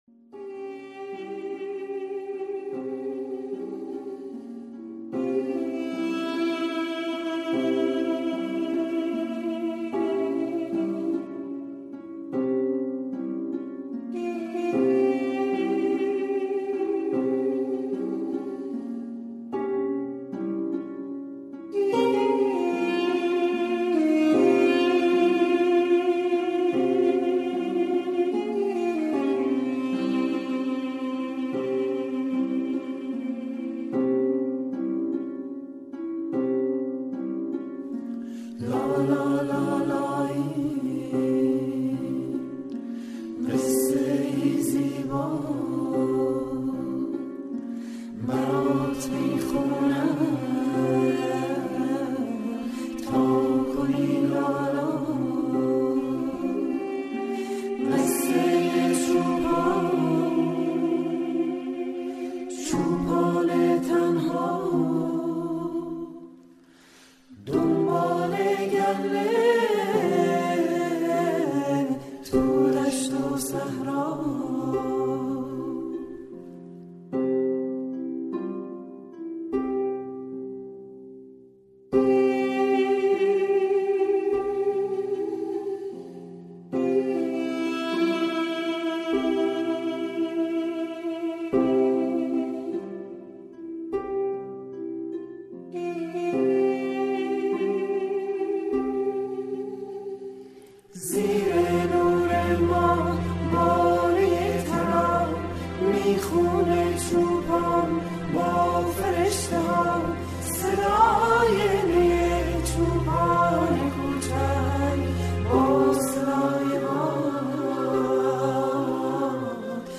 لالایی چوپان تنها
آهنگ لالایی